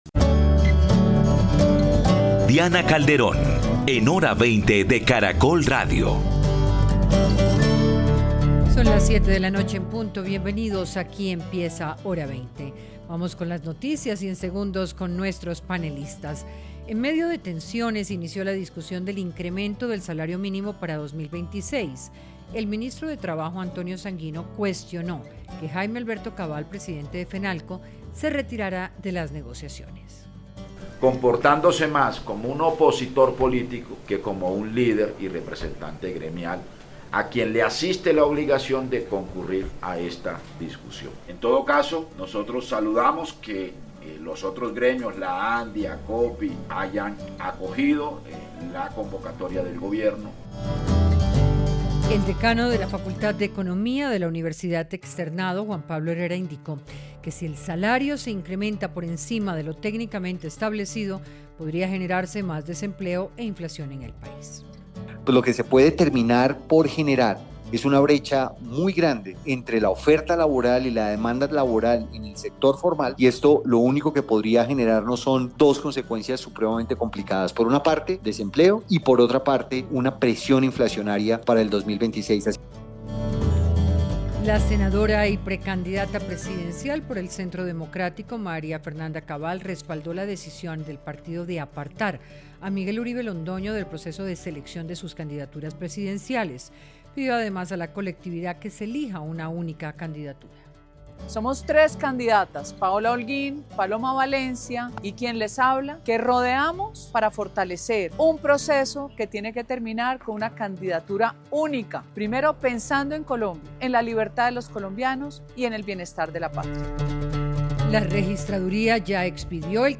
Panelistas analizaron a partir de la encuesta Invamer los escenarios en los que está la izquierda, el centro y la derecha de cara a las elecciones del 2026.